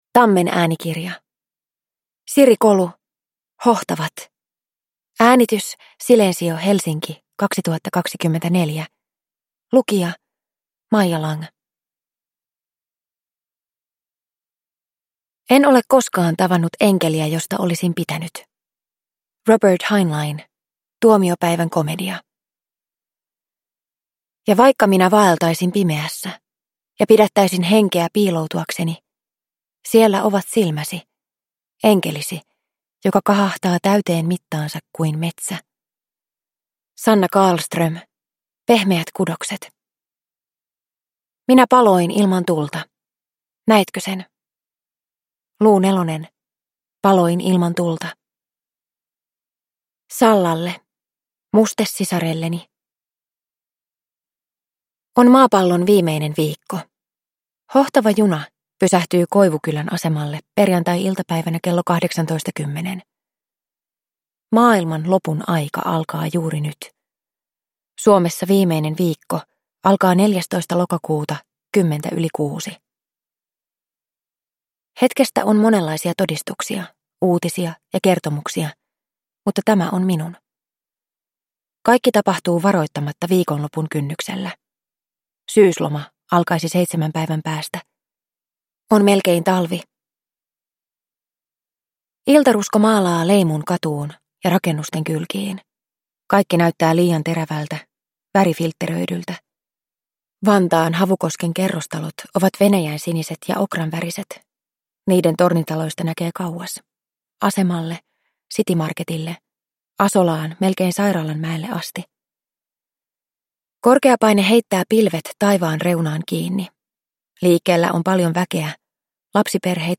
Hohtavat – Ljudbok